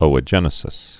(ōə-jĕnĭ-sĭs)